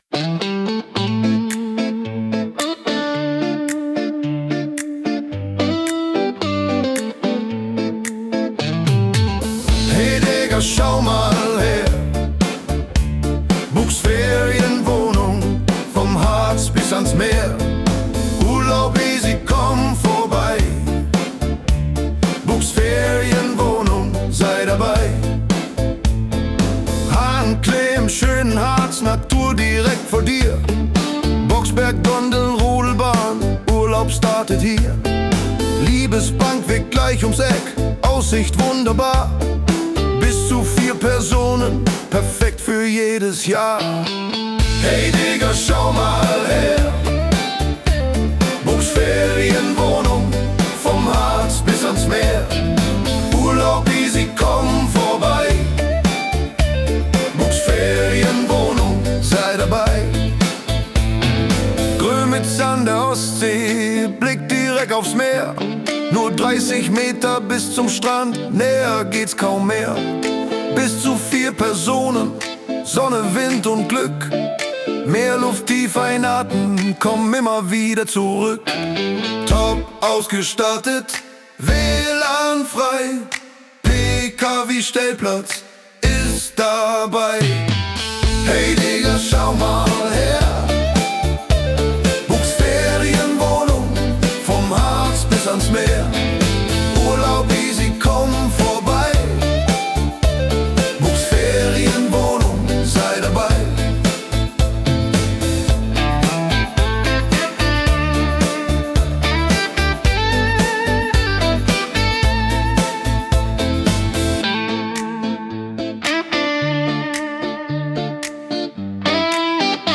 Unsere Werbung für die Ferienwohnungen.